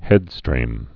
(hĕdstrēm)